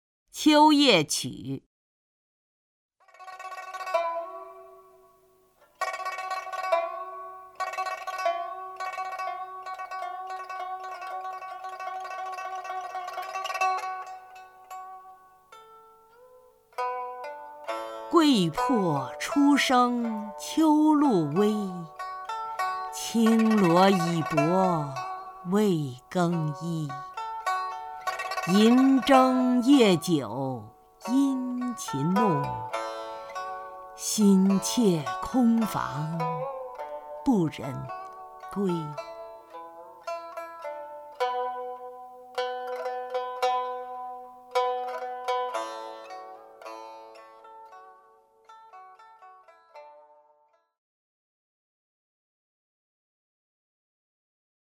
曹雷朗诵：《秋夜曲》(（唐）王维) (右击另存下载) 桂魄初生秋露微， 轻罗已薄未更衣。